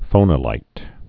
(fōnə-līt)